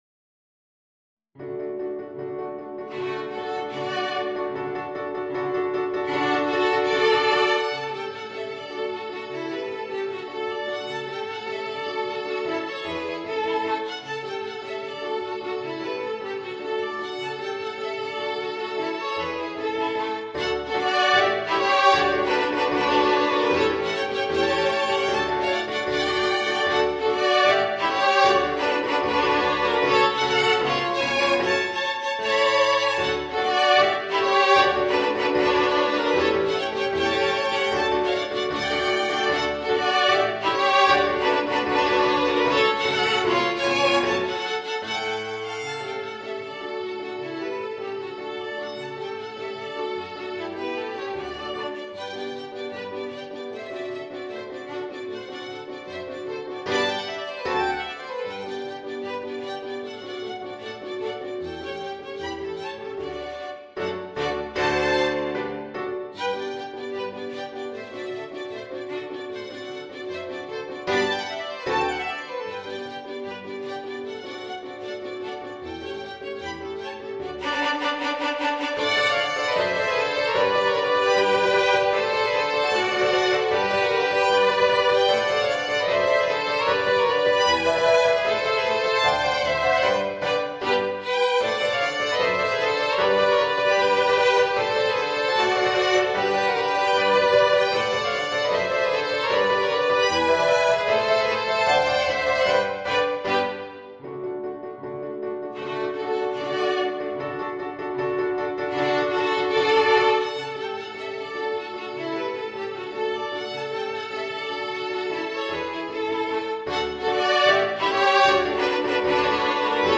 ヴァイオリン四重奏+ピアノ